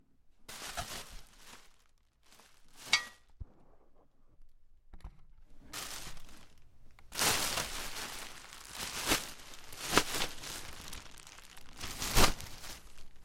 仓库内的声音 " I9塑料袋
描述：关闭塑料垃圾袋
标签： 塑料 垃圾袋
声道立体声